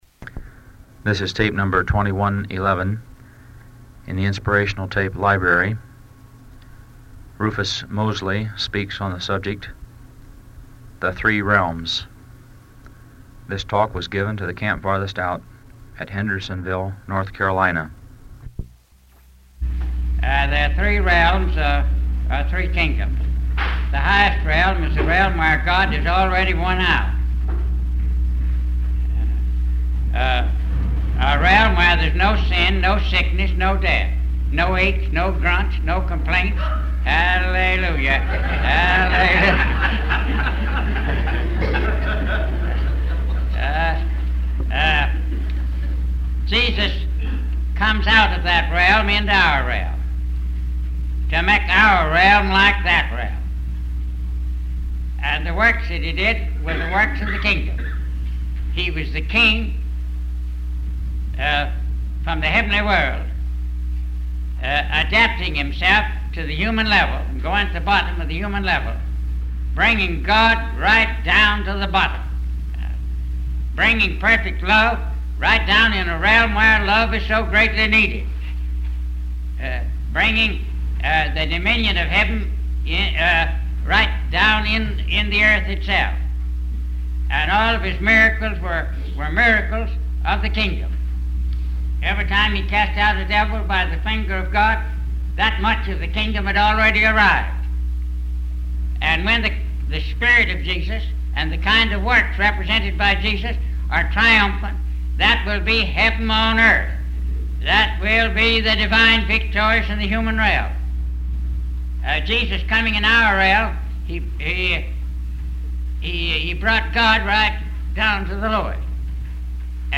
A talk on the "Three Realms"